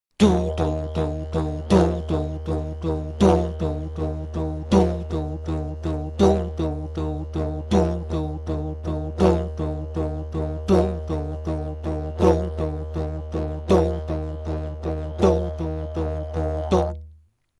didgeridoo tutorial - steam train
This exercise is pretty similar to the previous one, except that we now package the whole thing into a rhythmic form. Start counting “1-2-3-4” in a regular rhythm and then start with “Du/Doo” on the “1”.